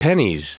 Prononciation du mot pennies en anglais (fichier audio)
Prononciation du mot : pennies